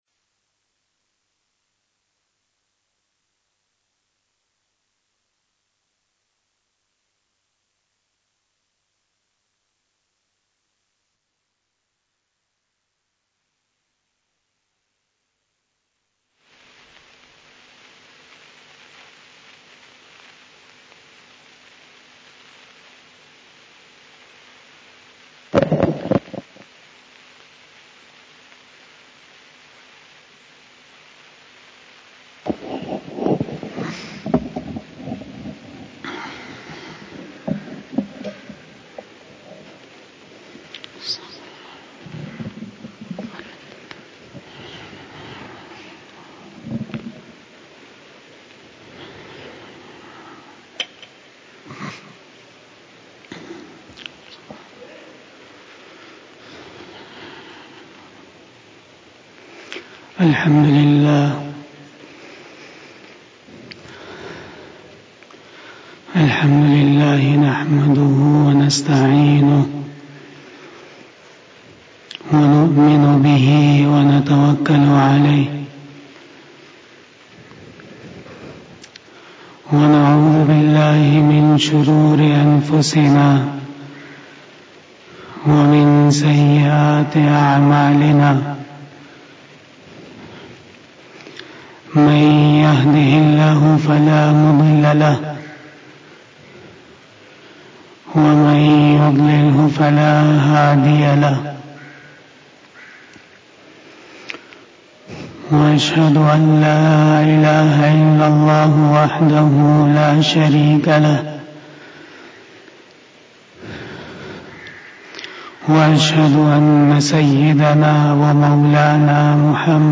Khitab-e-Jummah 2021